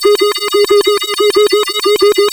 OSCAR 14 G#1.wav